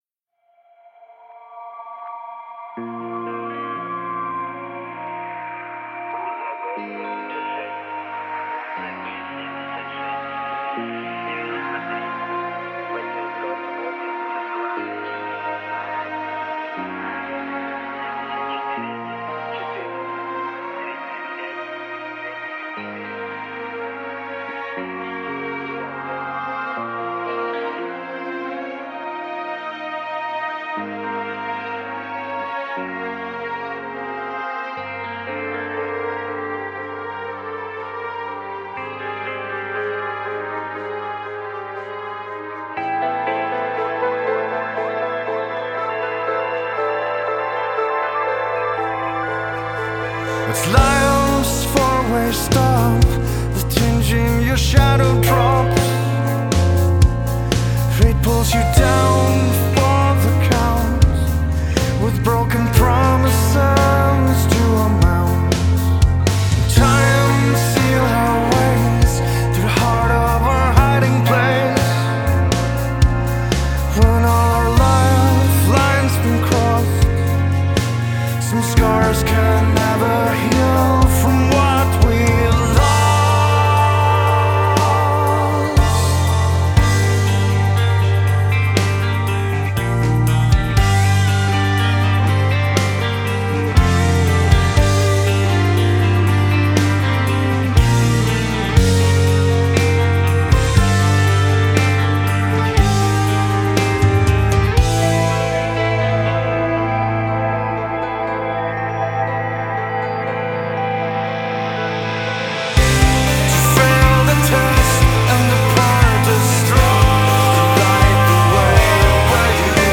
Genres: Progressive Metal
Prog metal